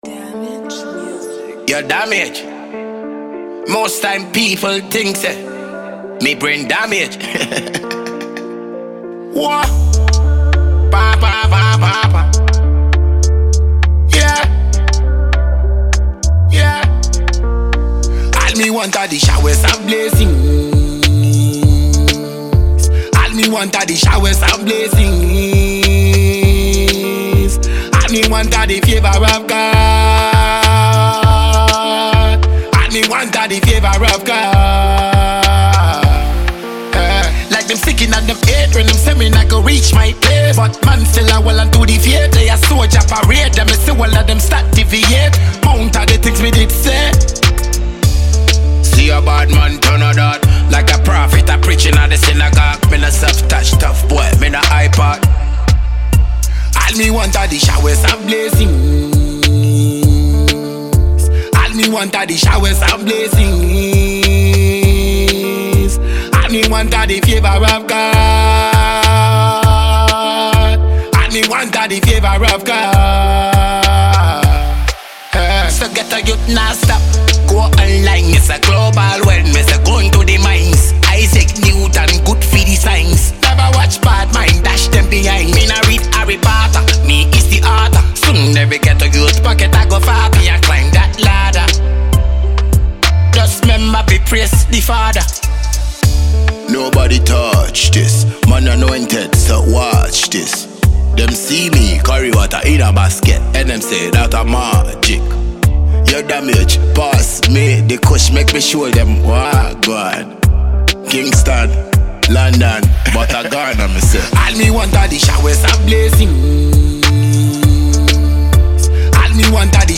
Dancehall/HiphopMusic
dancehall